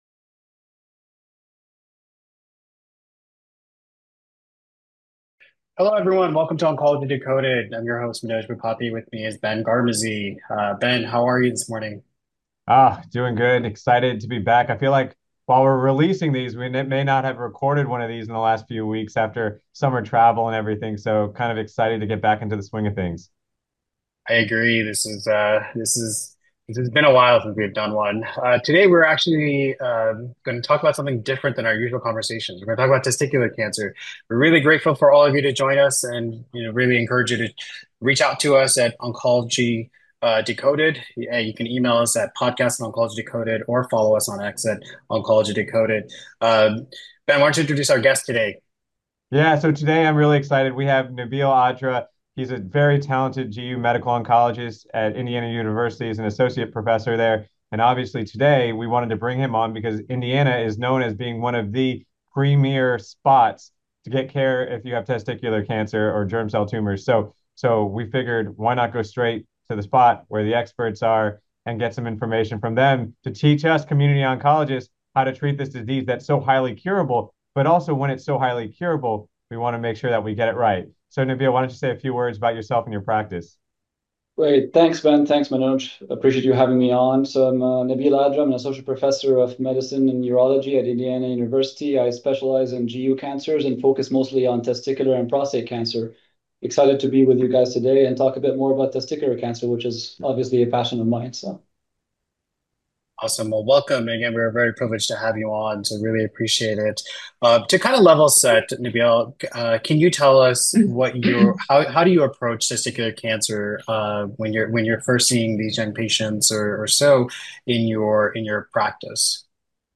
The discussion gave a comprehensive overview of managing germ cell tumors, offering practical pearls for community oncologists. The conversation opened with the initial approach to a patient presenting with a testicular mass.